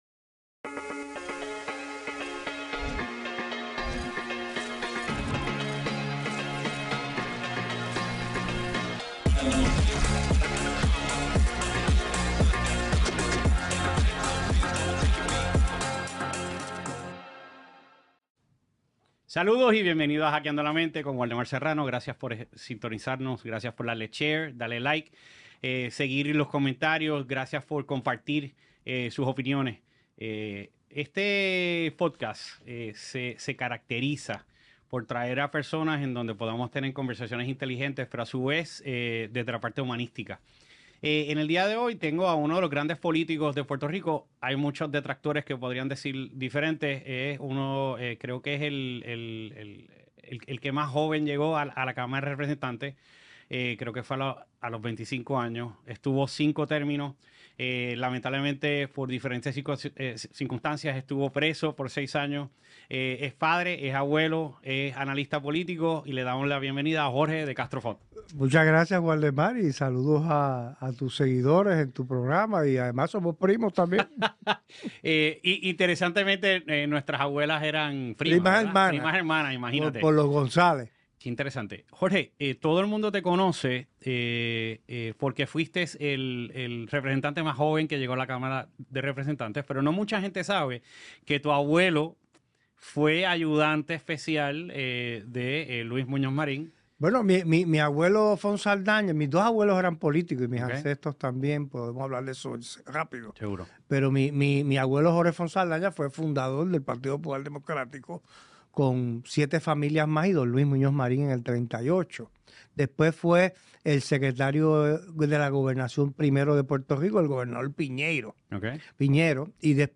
En este nuevo episodio de Hackiando la Mente presentamos una de las entrevistas mas profundas que el reconocido exsenador Jorge de Castro Font haya tenido en los últimos años.